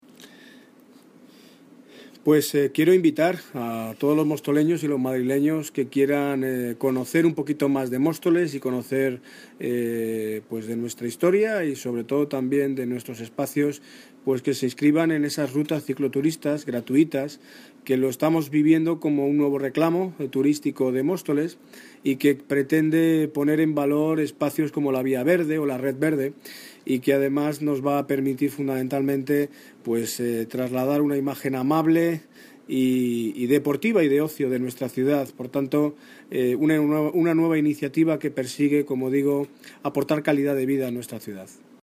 Audio - Daniel Ortiz (Alcalde de Móstoles) Sobre Rutas Ciclo turistas
Audio - Daniel Ortiz (Alcalde de Móstoles) Sobre Rutas Ciclo turistas.mp3